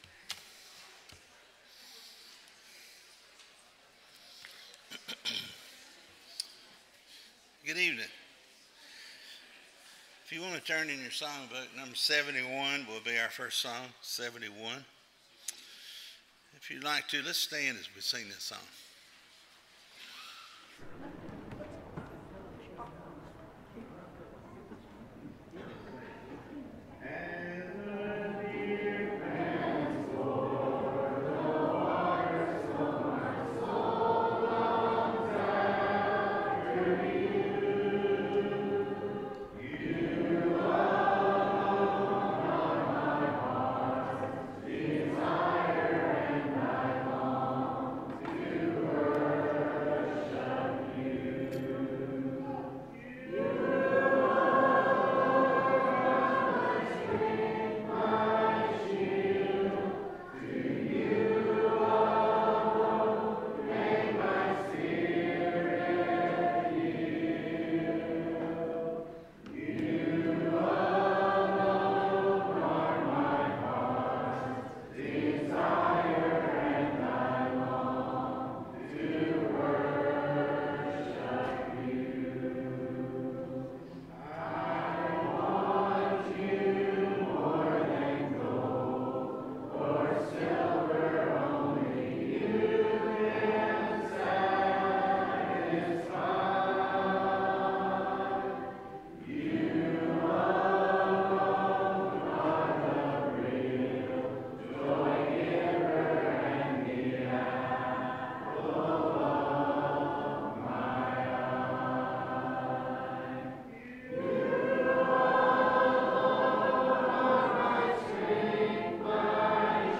Series: Sunday PM Service